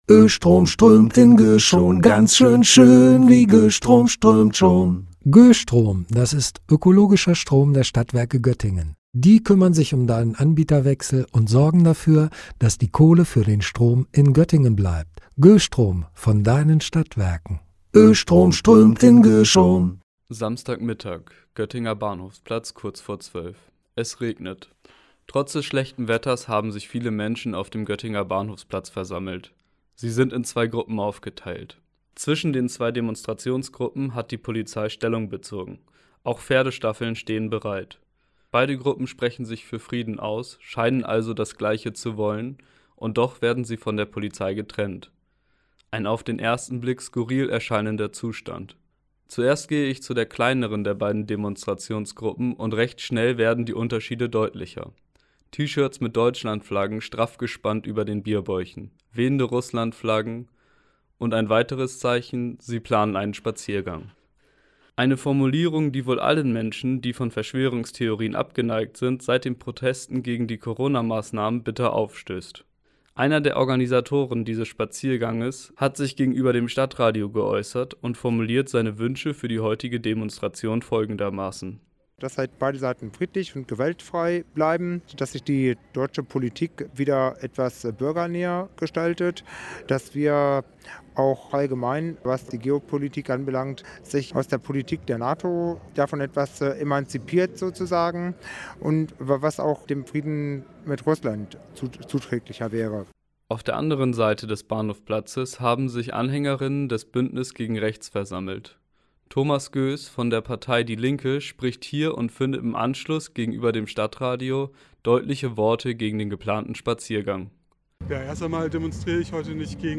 Am vergangenen Samstag, dem 01. April, gab es in Göttingen statt Aprilscherzen Demonstrationen. Zwei Bewegungen, die sich als Friedensbewegung deklarieren, buhlten an diesem Wochenende um die Aufmerksamkeit der Göttinger und Göttingerinnen.